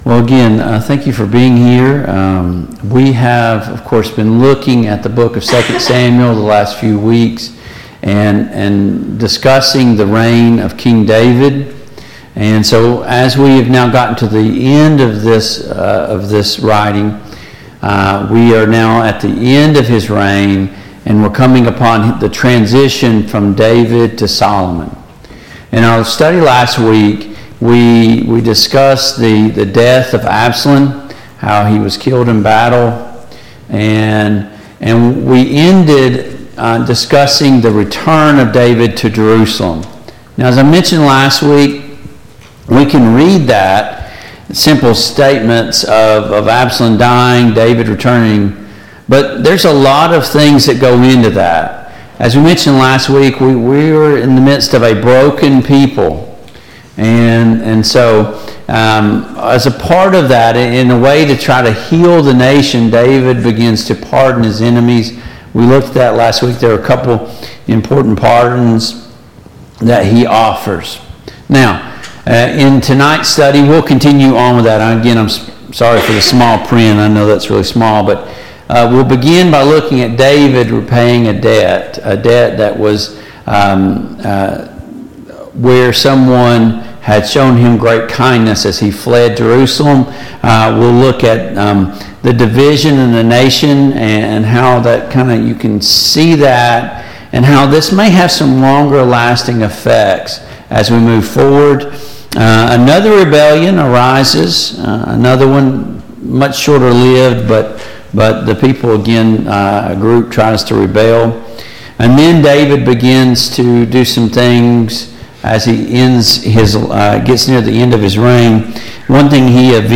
The Kings of Israel Passage: II Samuel 19, II Samuel 20, II Samuel 21 Service Type: Mid-Week Bible Study Download Files Notes « 5.